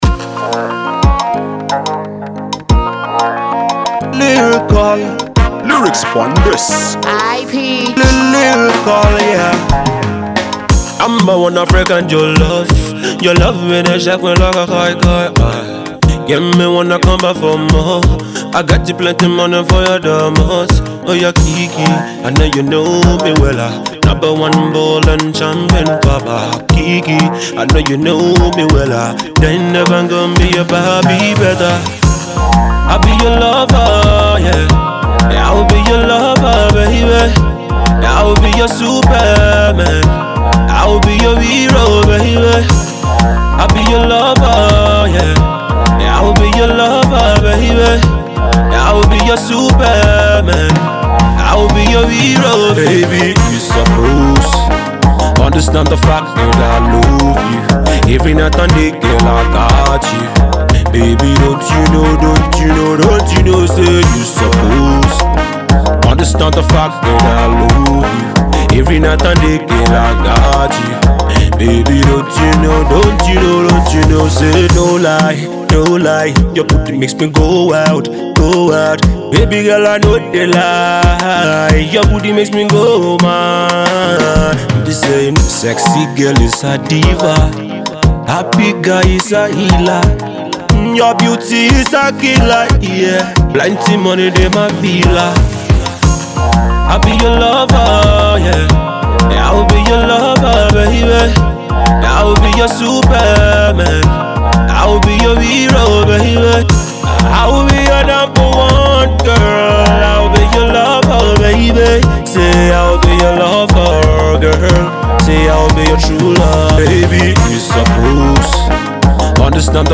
Afro-pop
energetic talented Afro-Pop singer
indian tune with a strong/powerful vocal ability